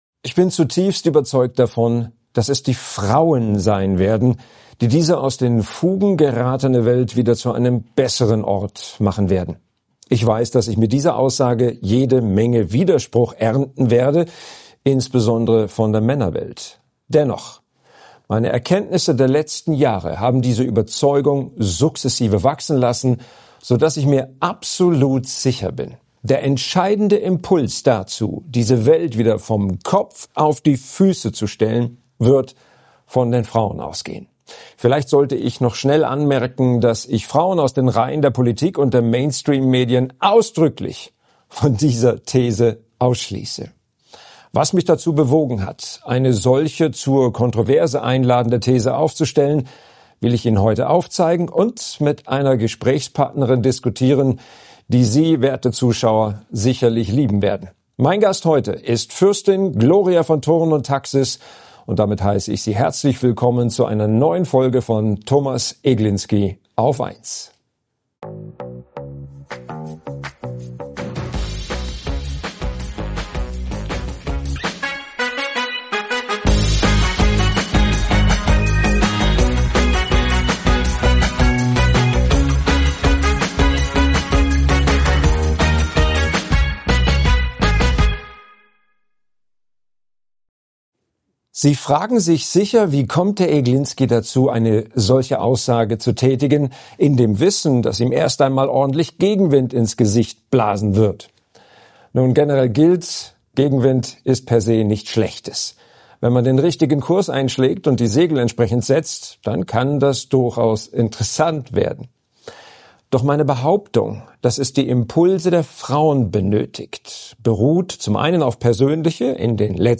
Fürstin Gloria von Thurn und Taxis spricht Klartext zur gesellschaftspolitischen Entwicklung in Deutschland. Die Bürger fordert sie auf, Freiheit und Demokratie gegen die Herrschenden zu verteidigen, denn diese streben nach mehr Kontrolle.